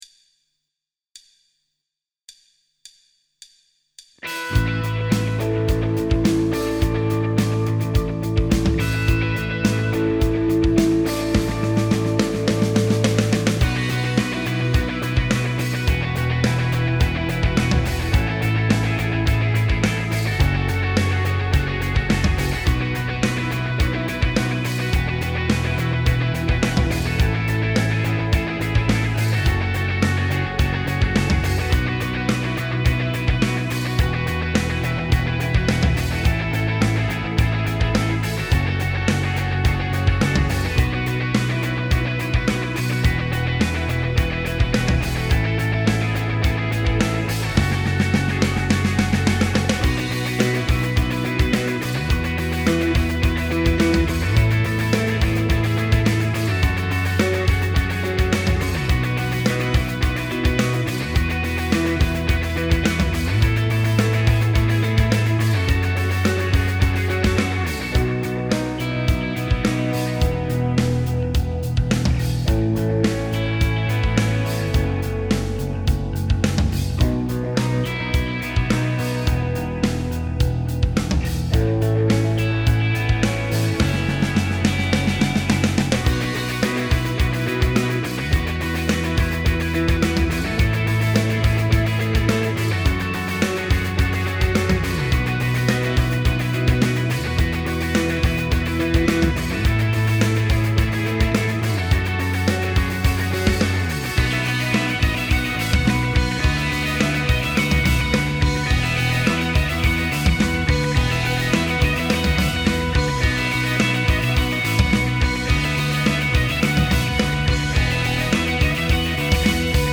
BPM : 106
Tuning : E
Without vocals
Based on the album version